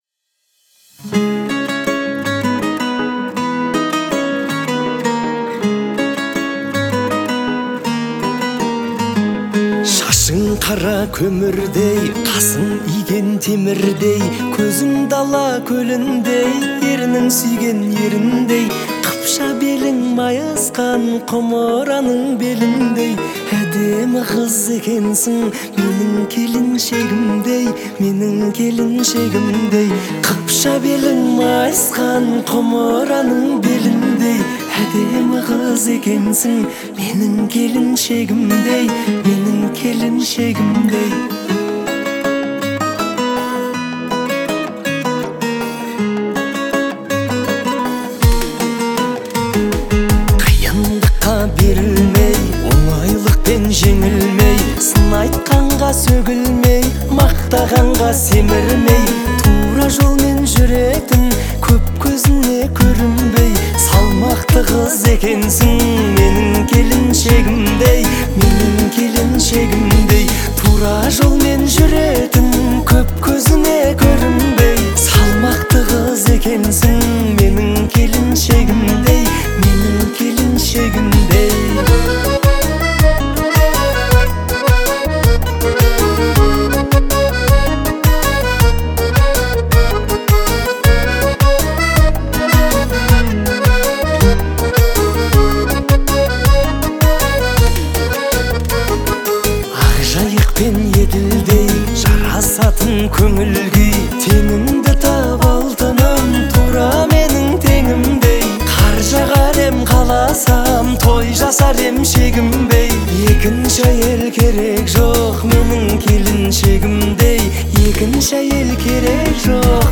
это романтическая песня в жанре поп